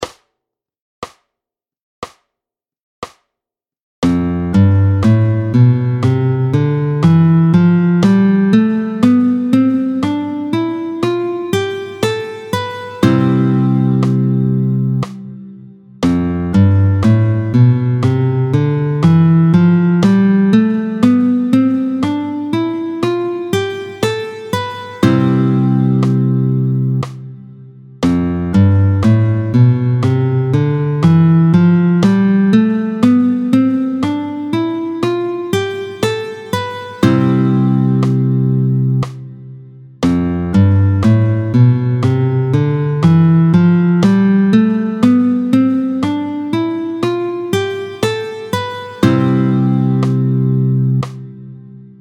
26-10 Doigté 4, Do majeur, tempo 60